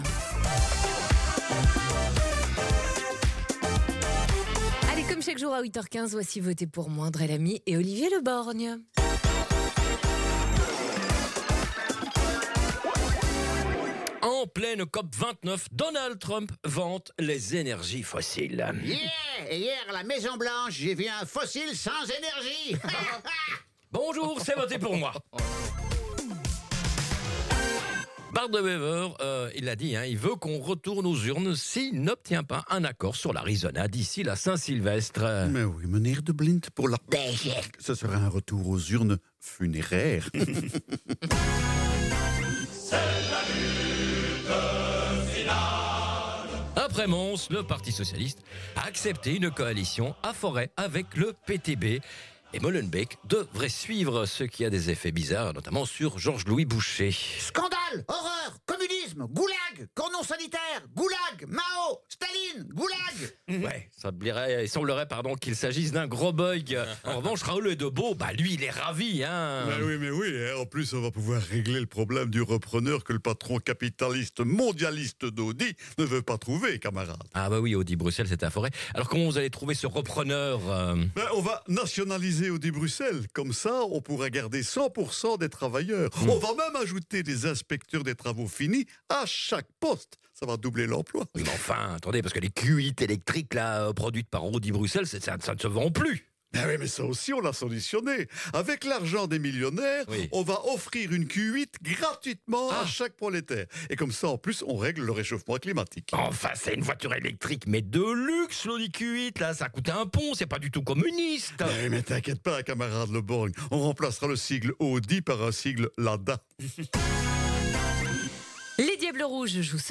Comédie
Bel RTL # Humour # Politique # Gouvernement # Elio # Joelle # Bart